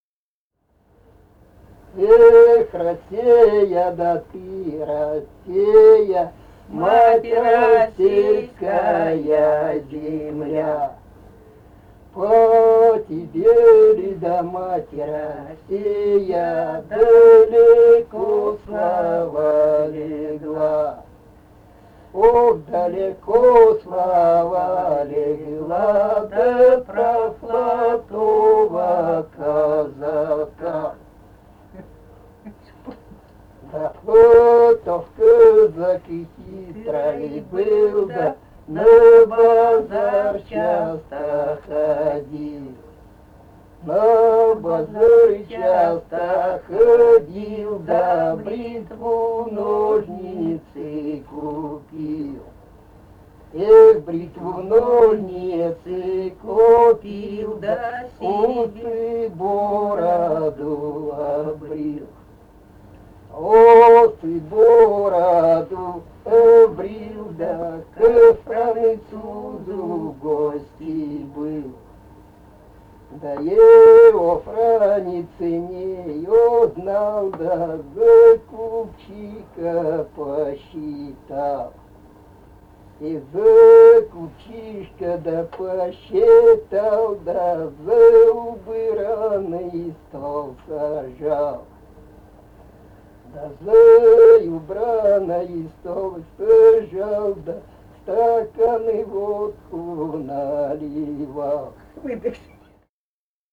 Этномузыкологические исследования и полевые материалы
«Эх, Расея, ты Расея» (историческая).
Алтайский край, Заимка Борзовая (округ г. Барнаула), 1967 г. И1016-04